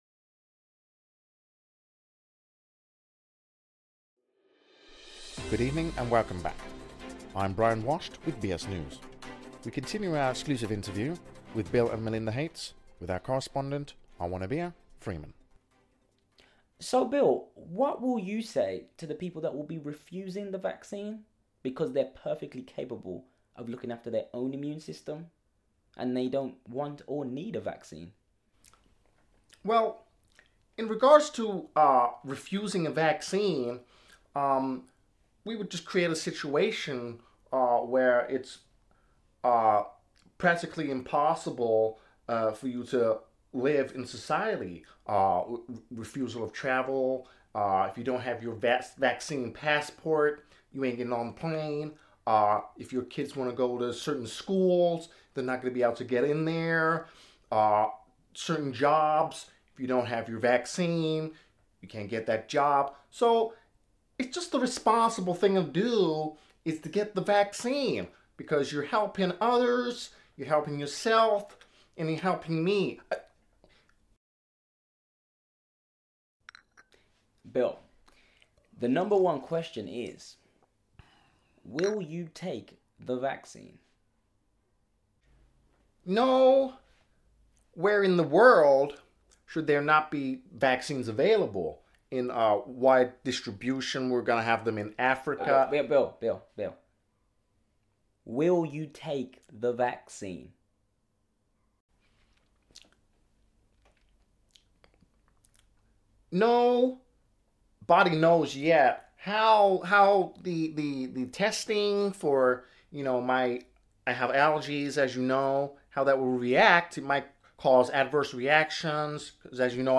Parody of Bill Gates Interviews | Worldwide exclusive!